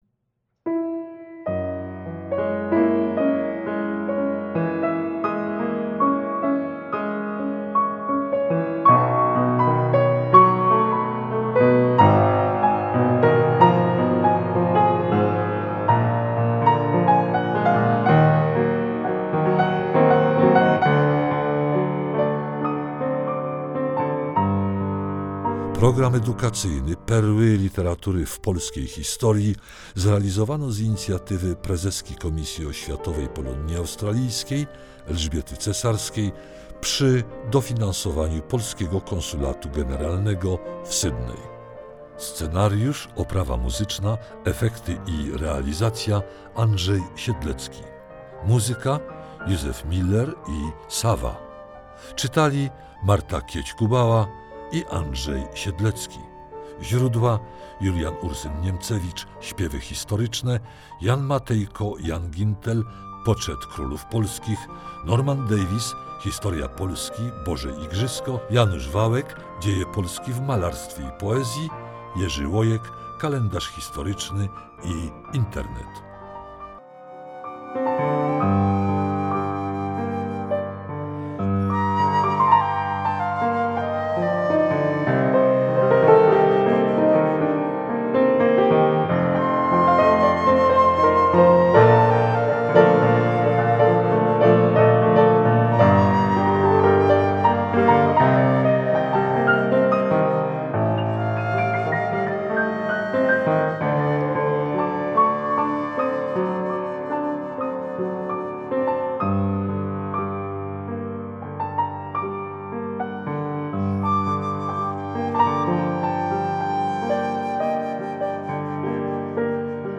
Wykonawcy Scenariusz, oprawa muzyczna, efekty i realizacja projektu
Recytacje